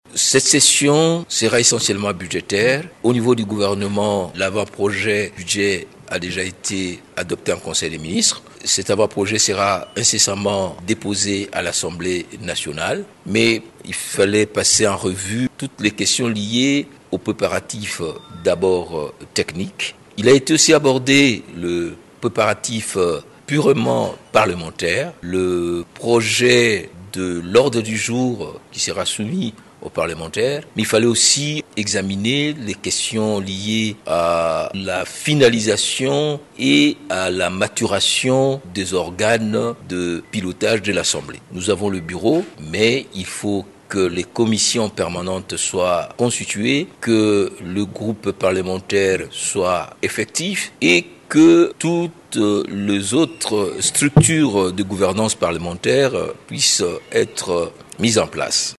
Vous pouvez écouter le rapporteur de l’Assemblée nationale, Jacques Djoli :